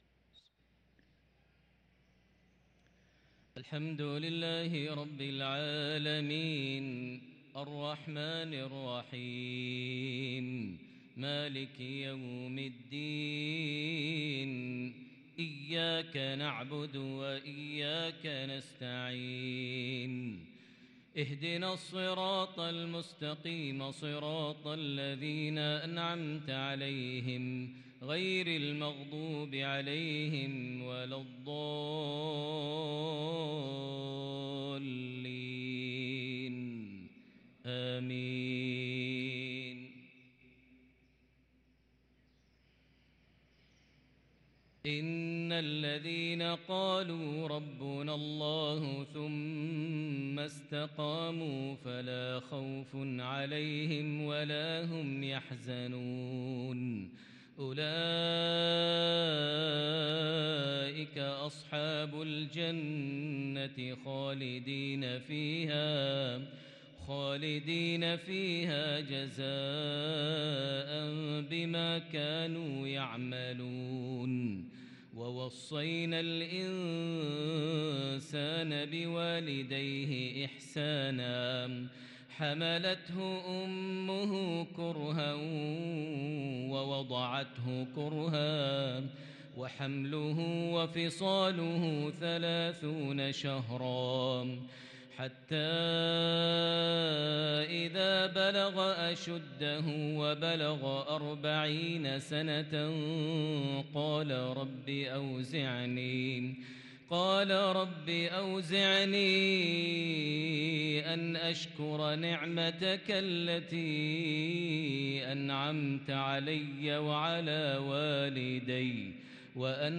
صلاة المغرب للقارئ ماهر المعيقلي 10 صفر 1444 هـ
تِلَاوَات الْحَرَمَيْن .